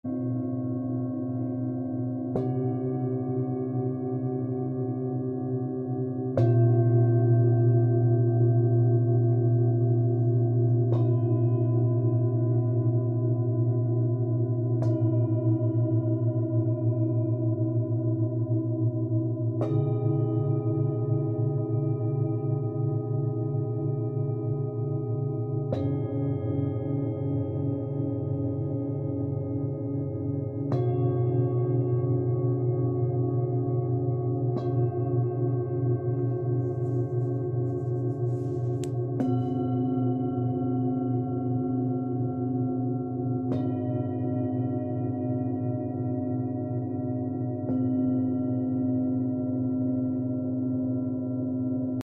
Revamp Your Life with Online Sound Bath & Meditation
Sound Bath Recording